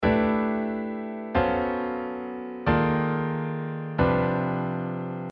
Minor third cyclic approach to target chord using altered dominants (#5, #9)
In the upper staff we have a tonal parallel chord motion while on the bottom staff I am moving the chords in minor third cycles